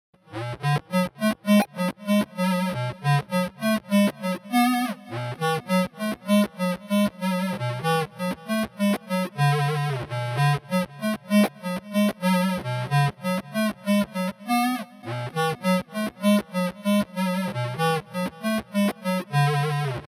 VSTi gitarový syntetizér
Ovladaju sa priamo audio signalom z gitary.
synth2.mp3